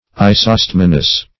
Meaning of isostemonous. isostemonous synonyms, pronunciation, spelling and more from Free Dictionary.